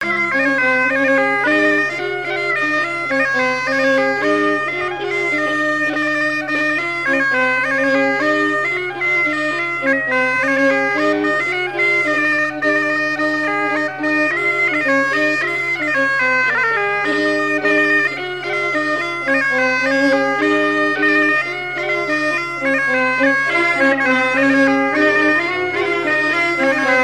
Rond de Sautron par Sonneurs de veuze
Fonction d'après l'analyste danse : ronde : grand'danse ;
Airs joués à la veuze et au violon et deux grands'danses à Payré, en Bois-de-Céné
Catégorie Pièce musicale inédite